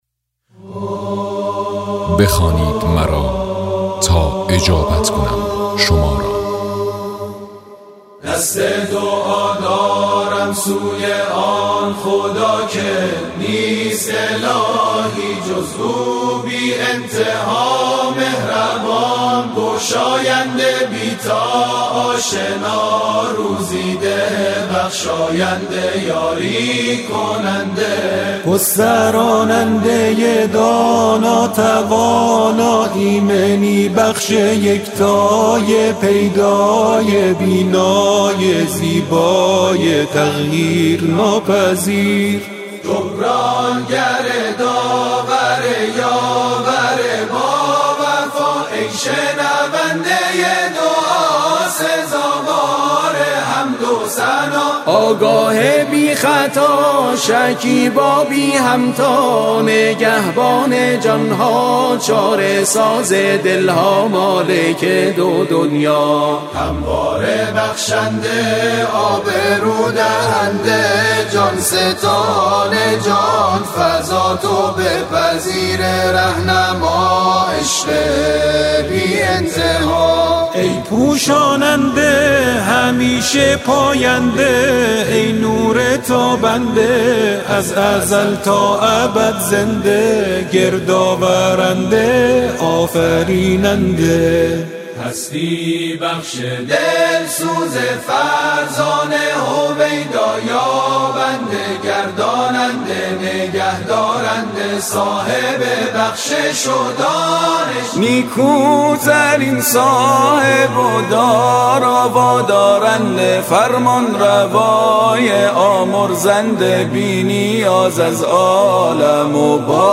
سرودهای ماه رمضان